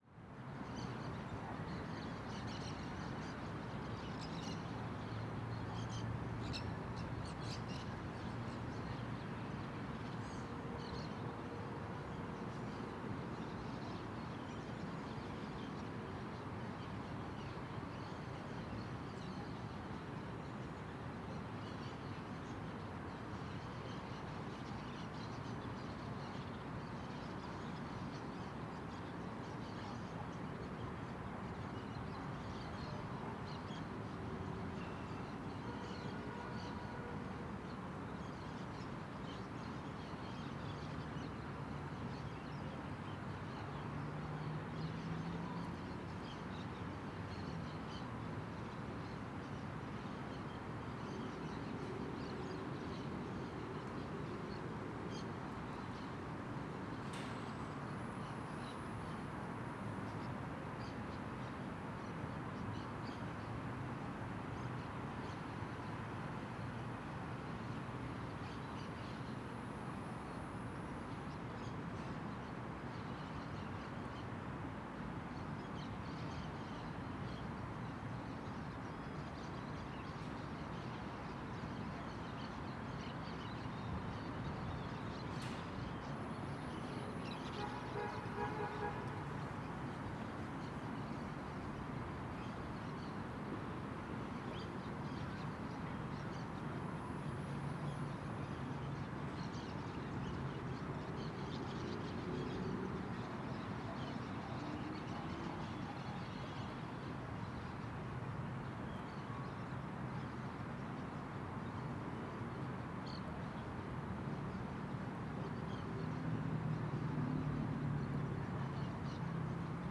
CSC-04-131-OL- Rumble cidade dia desde terraco de predio com maritacas.wav